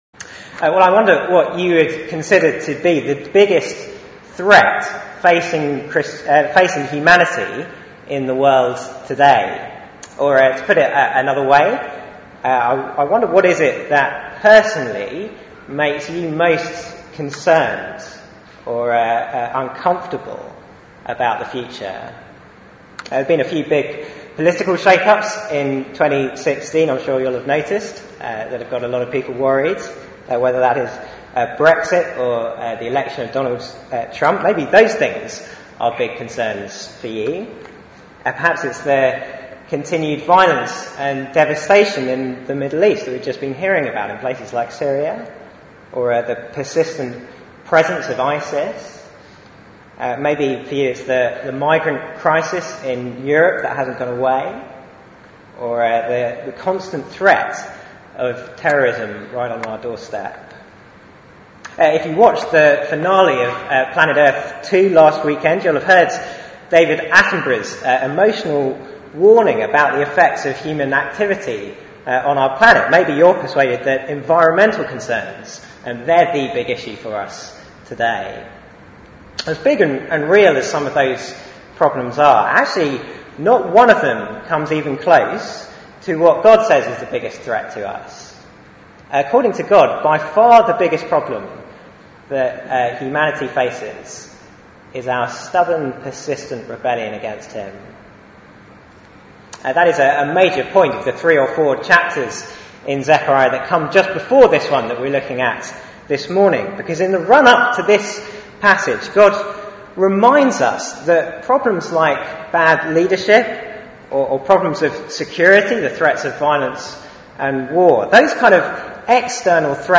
Series: 4 Pictures for Advent Theme: A shepherd struck and sheep scattered Sermon